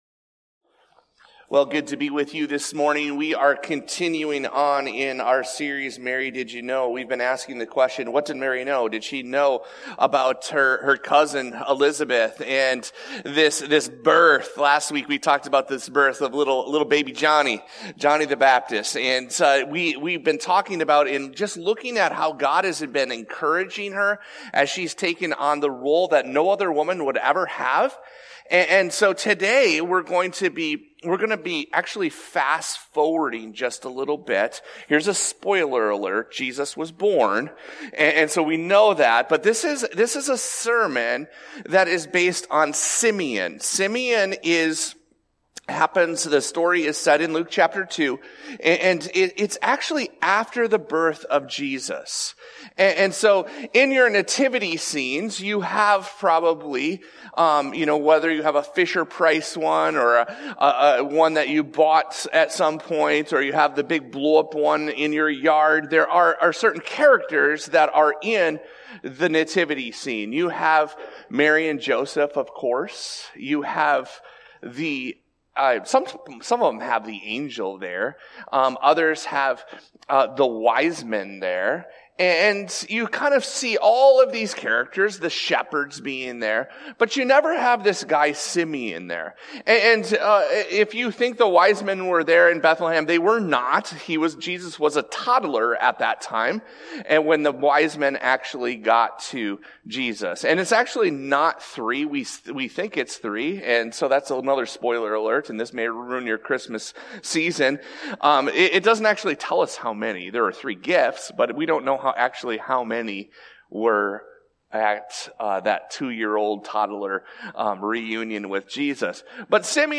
This podcast episode is a Sunday message from Evangel Community Church, Houghton, Michigan, December 15, 2024.